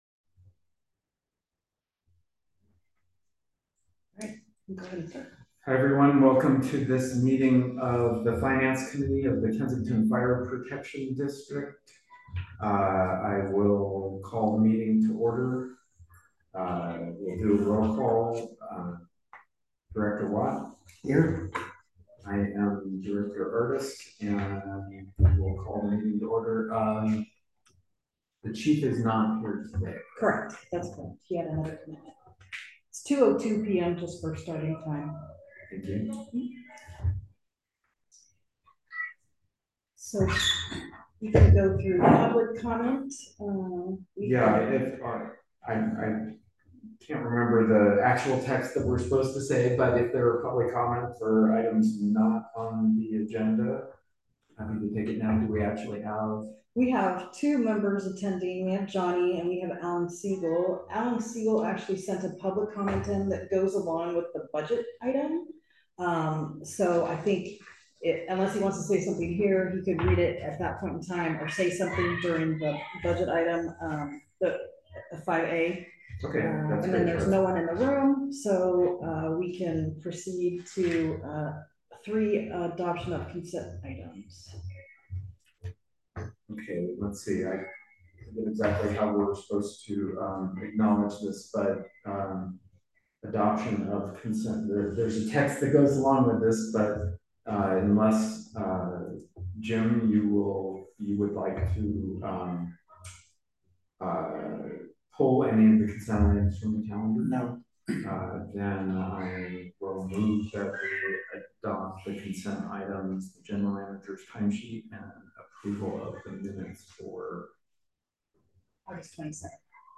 Finance Committee Meeting